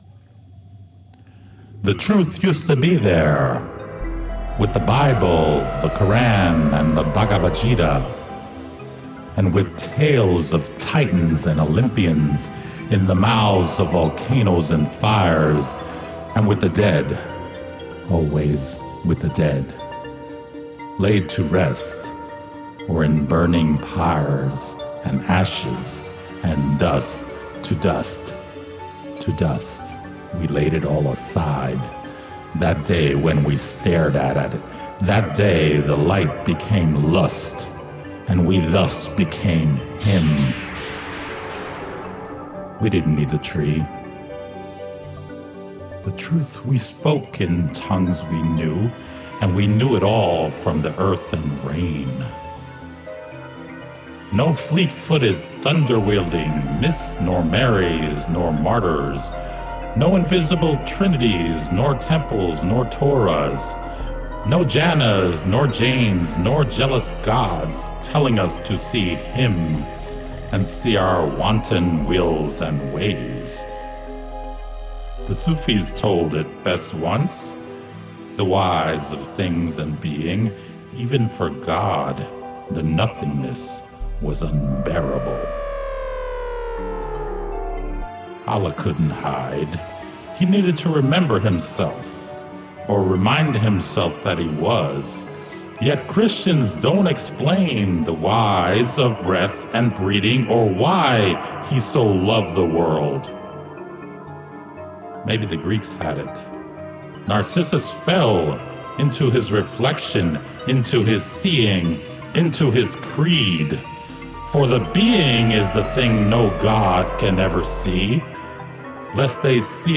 All voices, sounds and effects were created by me as well.